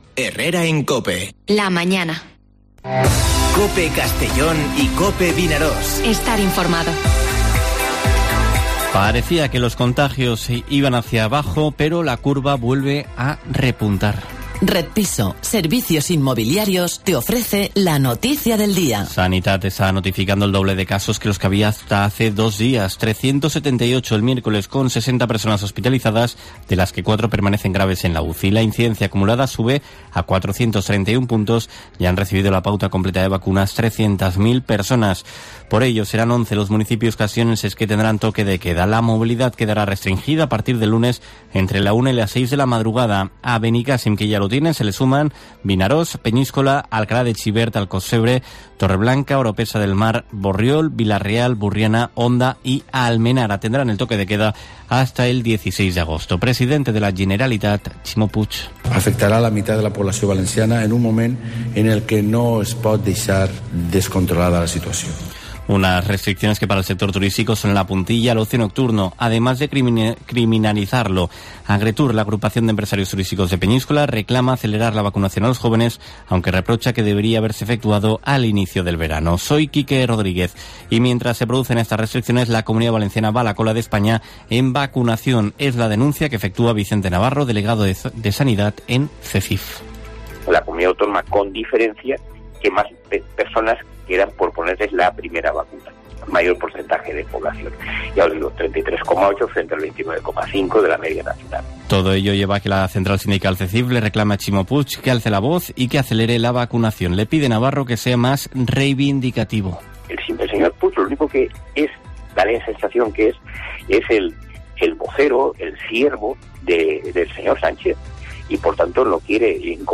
Informativo Herrera en COPE en la provincia de Castellón (23/07/2021)